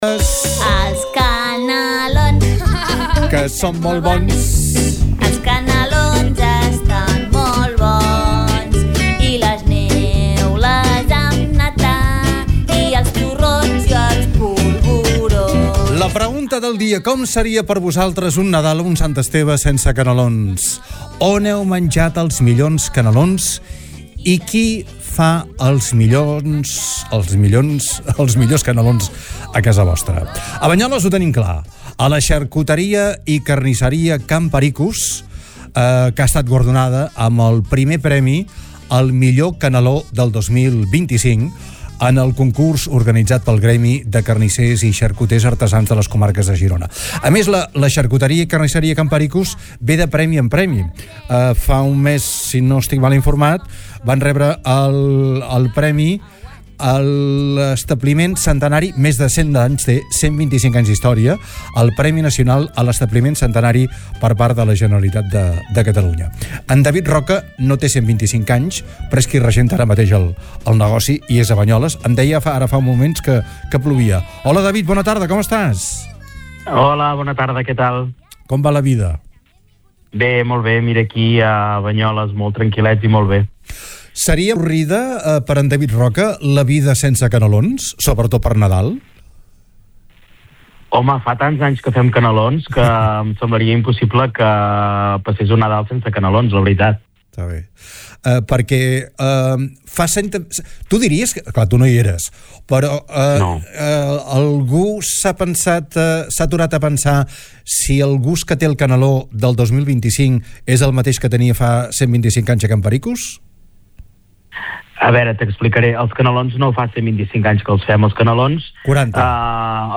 ha estat entrevistat al programa DE CAP A CAP i ha destacat que “ens fa especial il·lusió que se’ns hagi premiat pels nostres canelons,una recepta de tota la vida que fa més de 40 anys que elaborem.